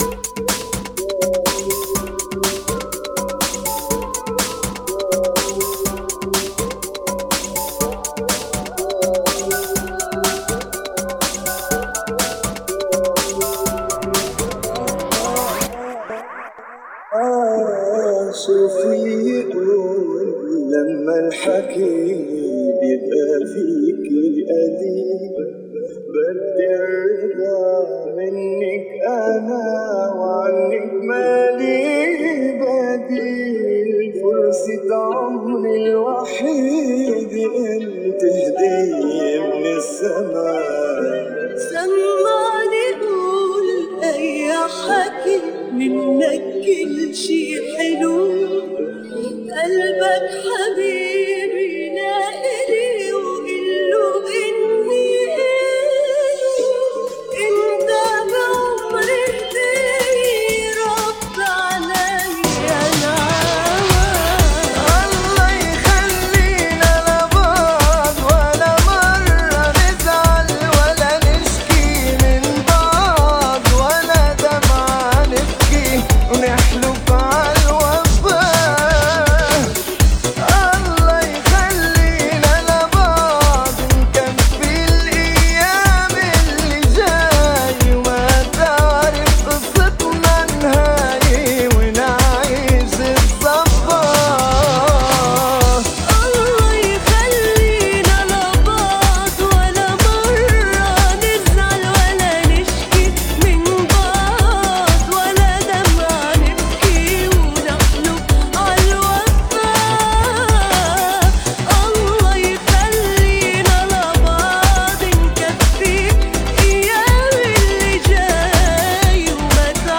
Funky [ 123 Bpm ]